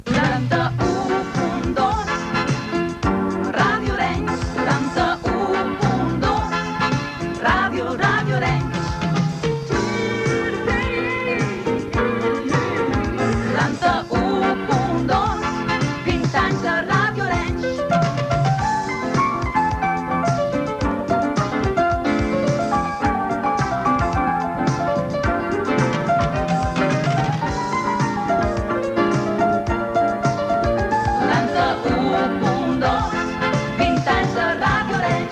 Indicatiu dels 15 anys de l'emissora.